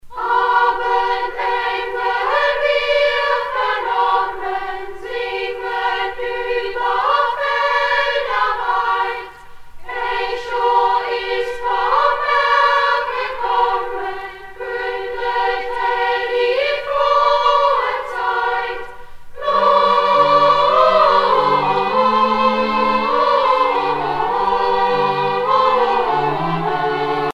Noël
Pièce musicale éditée